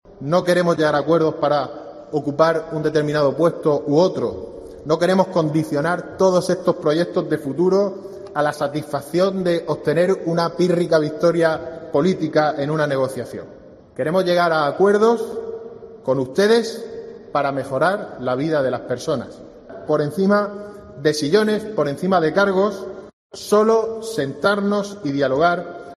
Joaquín Segado, portavoz del Grupo Parlamentario Popular